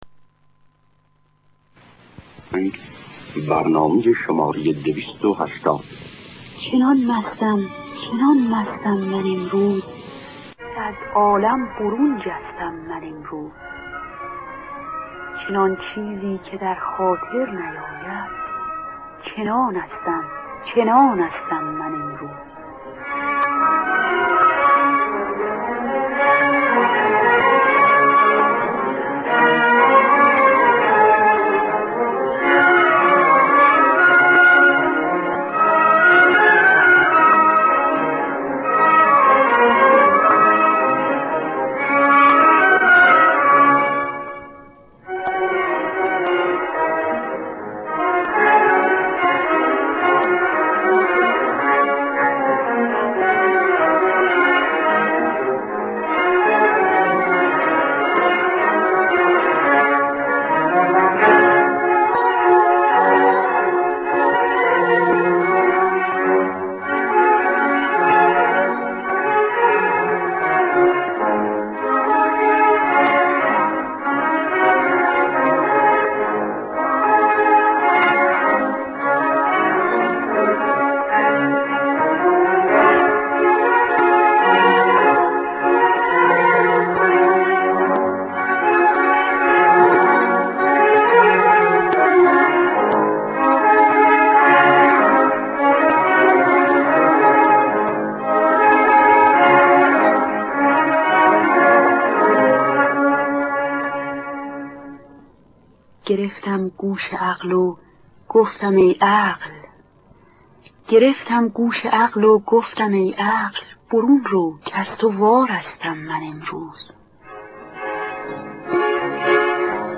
در دستگاه ماهور.